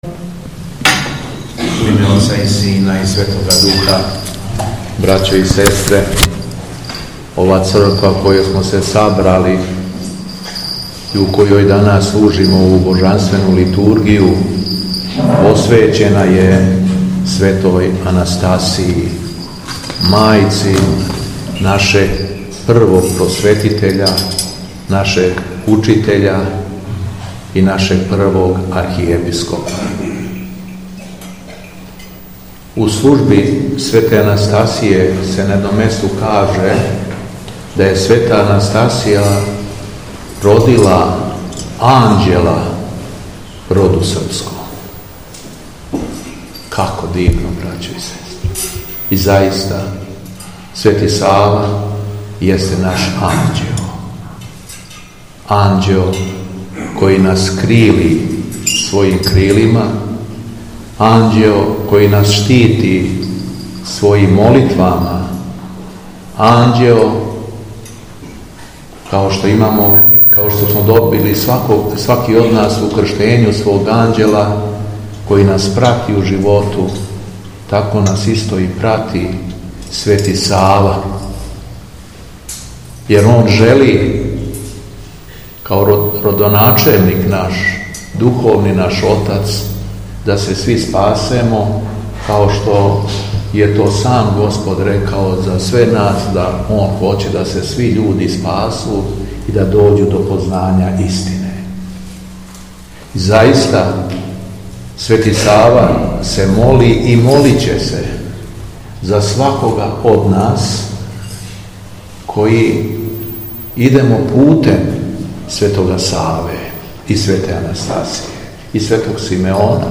ХРАМОВНА СЛАВА ХРАМА „СВЕТА АНАСТАСИЈА СРПСКА“ У КОПЉАРИМА - Епархија Шумадијска
Беседа Његовог Високопреосвештенства Митрополита шумадијског г. Јована